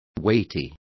Complete with pronunciation of the translation of weighty.